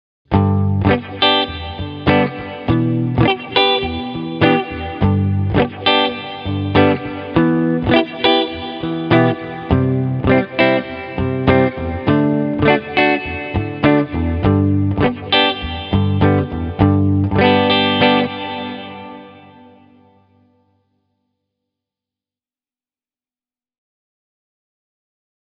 Se on mielestäni erittäin mielenkiintoinen efekti, jolla saa aikaiseksi ihan omalla, houkuttelevalla tavalla pulputtavaa jälkikaikua – jossain moduloidun delayn ja digikaiun ensiheijastuksien väliltä.
Voin kuitenkin todeta, että pidän suuresti tämän efektin pehmeästä soundista, joka on selvästi hieman erikoinen, mutta joka samalla ei peitä varsinaista soittoa alleen.
Tässä esimerkki lyhyellä S-Time-asetuksella: